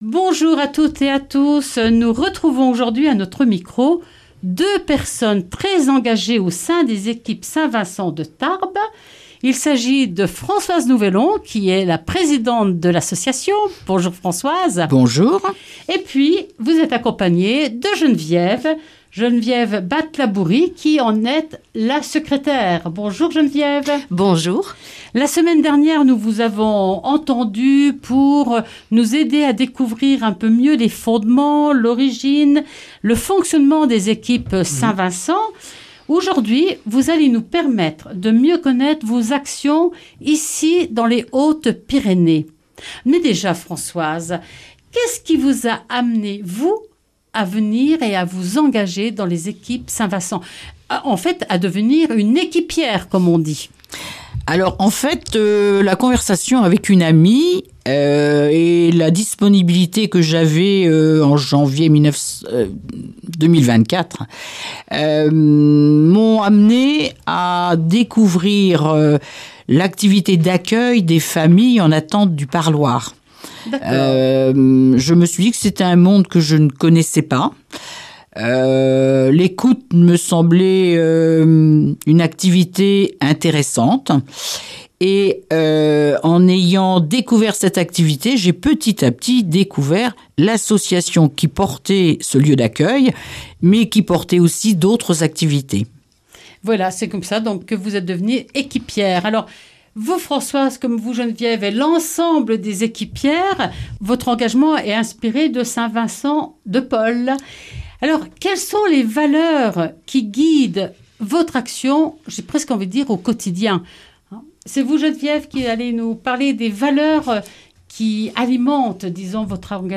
Deux invités engagés aux équipes St Vincent de Tarbes vont nous faire découvrir leur activité au sein de cette association.